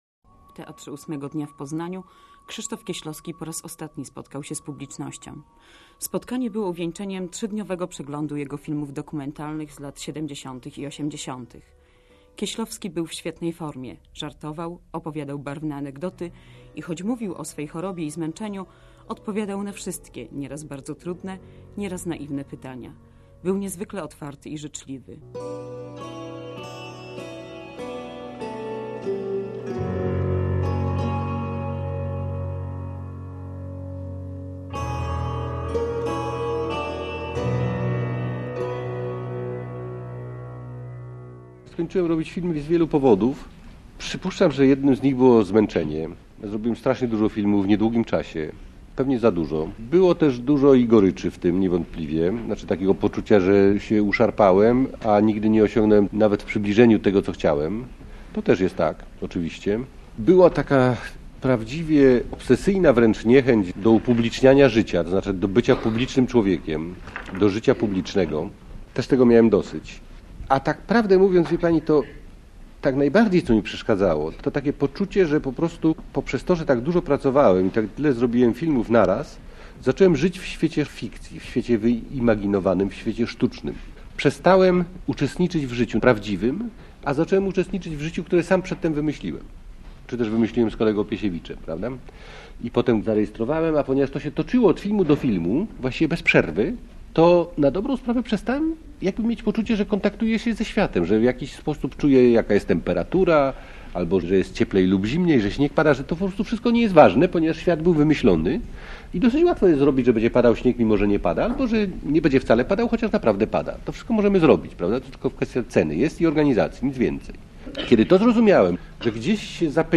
W sobotę na naszej antenie ostatnia zarejestrowana rozmowa z Krzysztofem Kieślowskim.